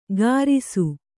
♪ gārisu